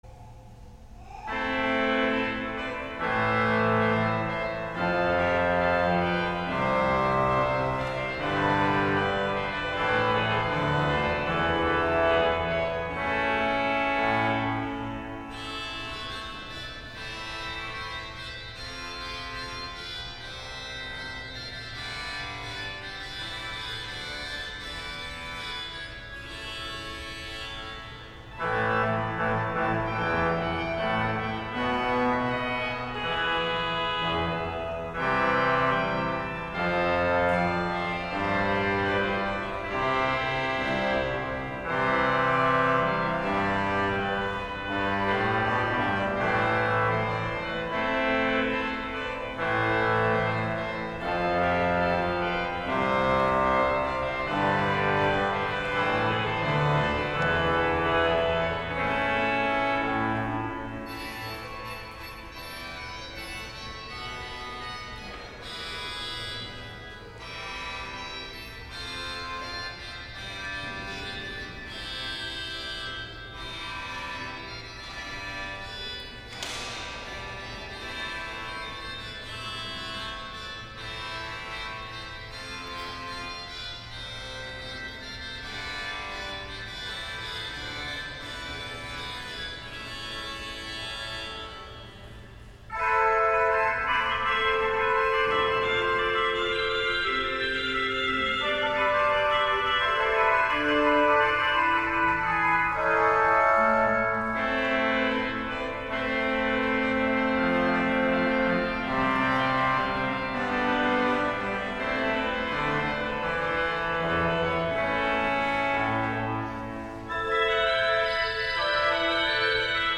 Órgano del Sol Mayor